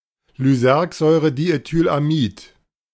Le diéthyllysergamide, connu sous l'abréviation LSD ou LSD-25 (de l'allemand Lysergsäurediethylamid[5] [lyˈzɛʁkzɔɪ̯ʀədiʔetyːlʔaˌmiːt]
De-Lysergsäurediethylamid.ogg.mp3